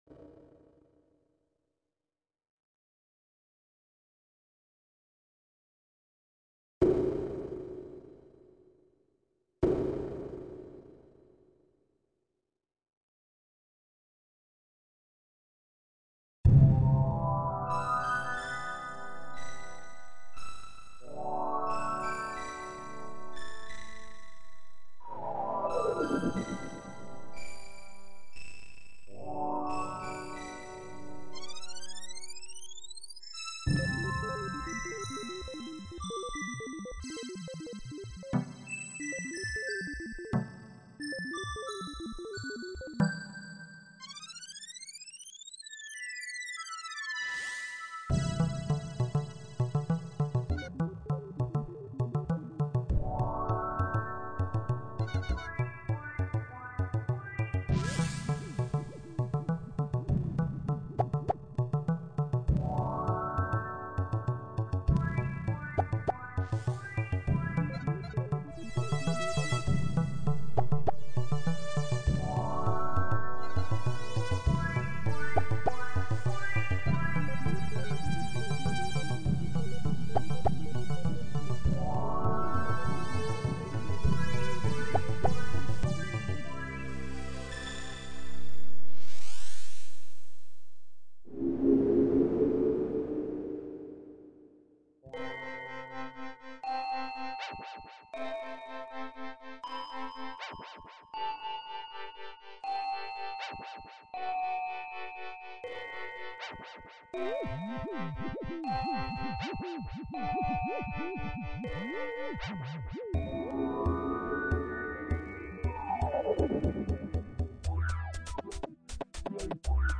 music compositions
SY85 is an all-in-one synthesizer produced by YAMAHA in 1992.
My first and longest experience of multitrack sequencer was on SY85.